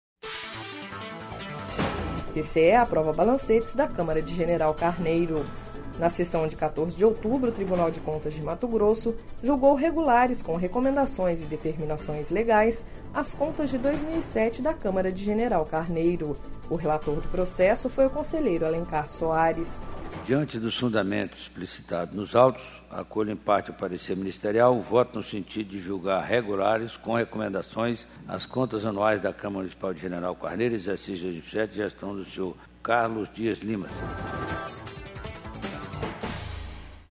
Sonora: Alencar Soares – conselheiro do TCE-MT